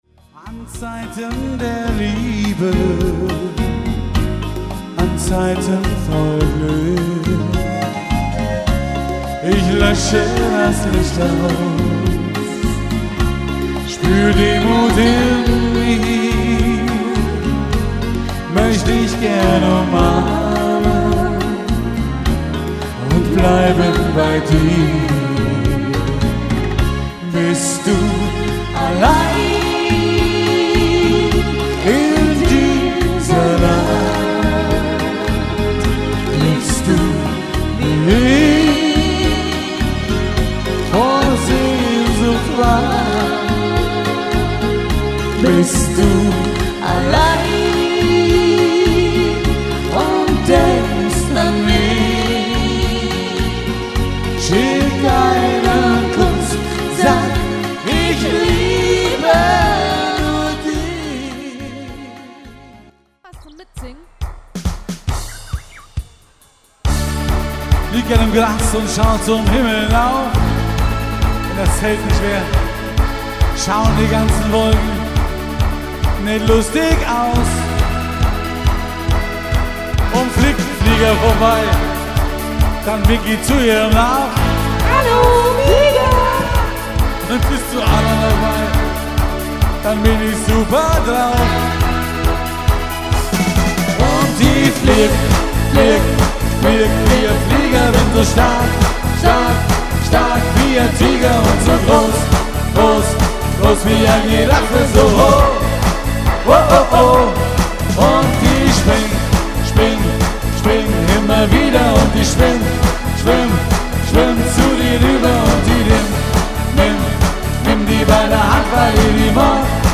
LIVE-Mitschnitte 2010